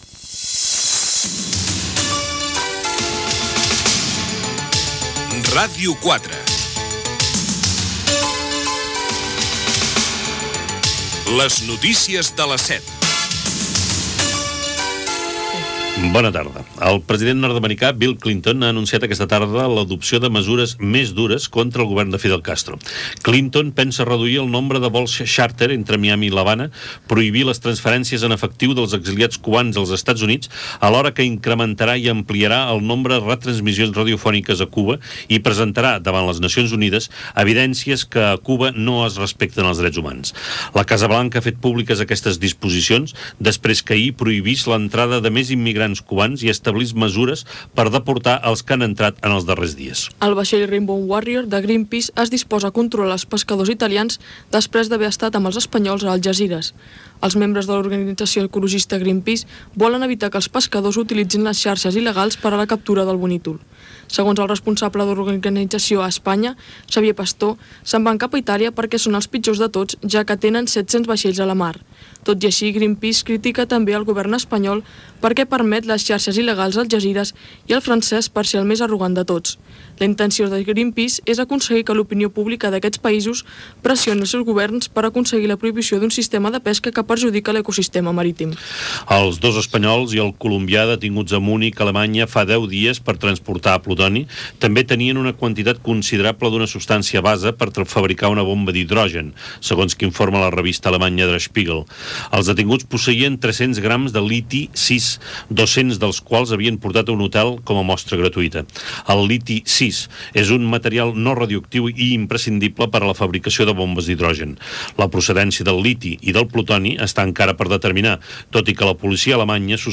Informatiu
Presentador/a
FM